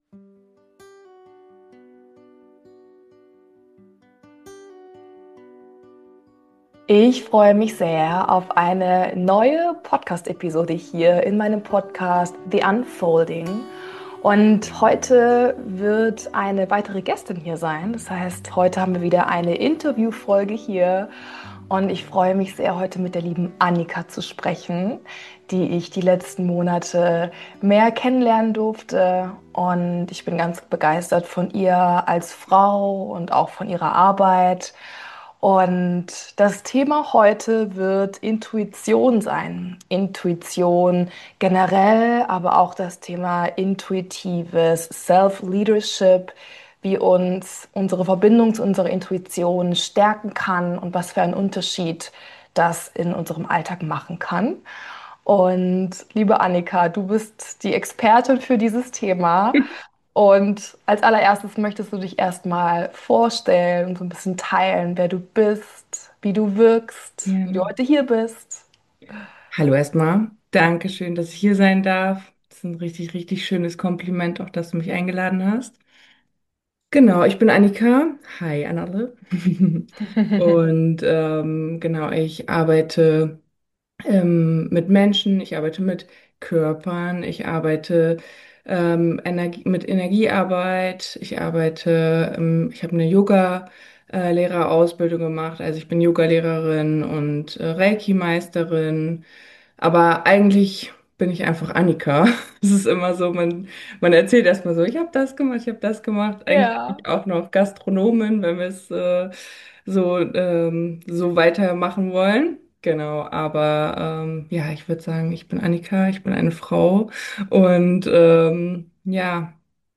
Wir hoffen, das Podcast-Interview kann dich inspirieren und zu neuen Perspektiven einladen.